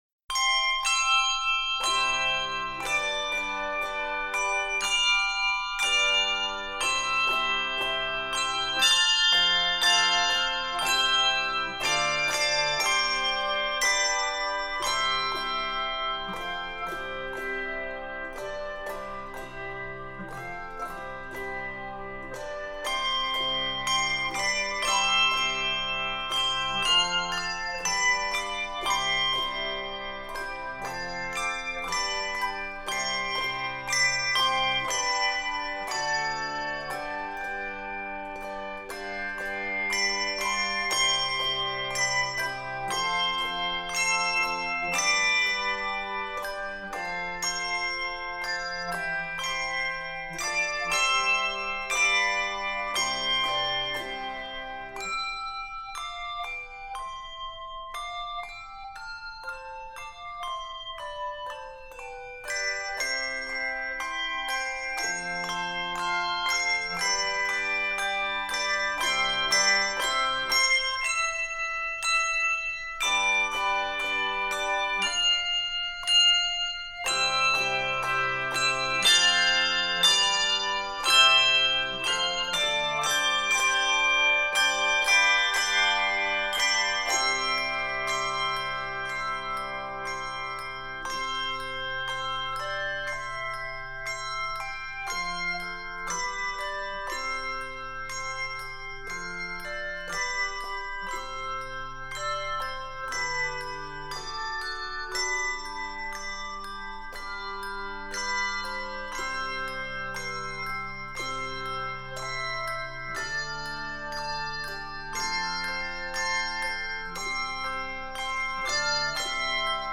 stately hymn
Key of C Major.